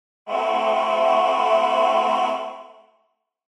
Click to hear a minor6 chord.
am6_chord.mp3